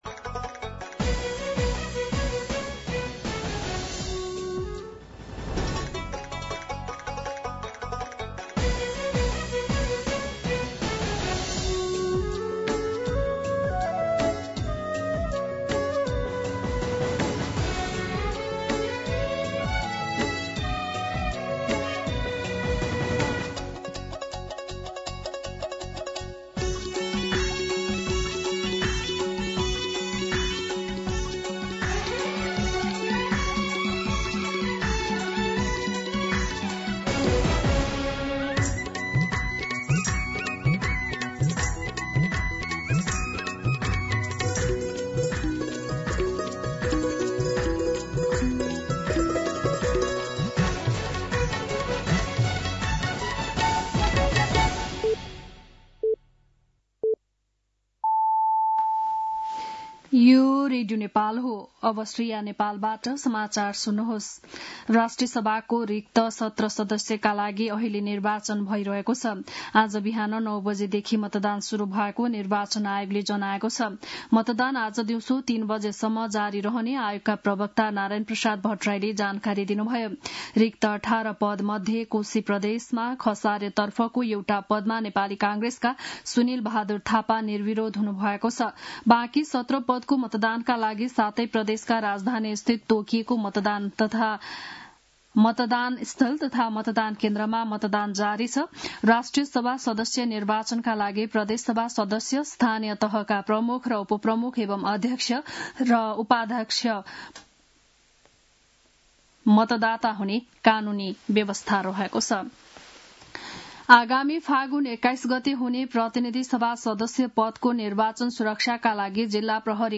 बिहान ११ बजेको नेपाली समाचार : ११ माघ , २०८२